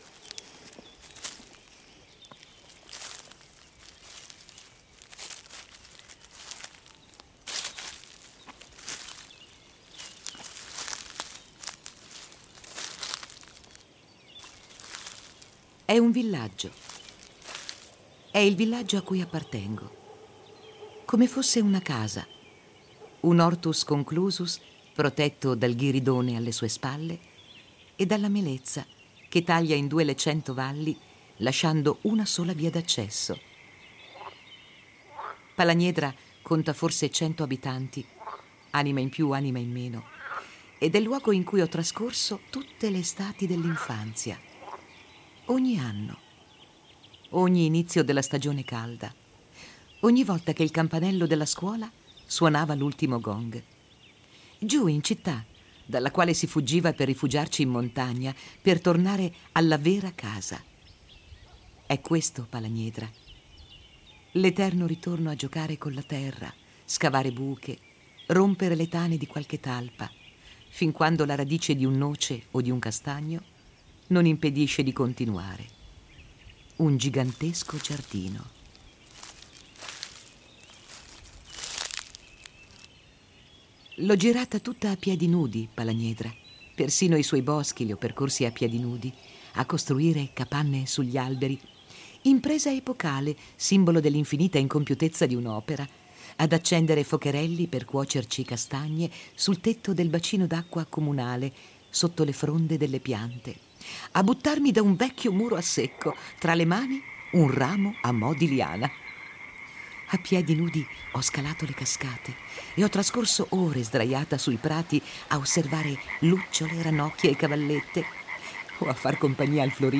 «Geografie di…» ha voluto raccogliere per ciascuno un «racconto breve che potesse mettere in evidenza un luogo a loro caro». Racconti che poi sono stati letti e trasformati in audioracconti, perché la loro destinazione finale non è stata un libro, bensì una trasmissione radiofonica.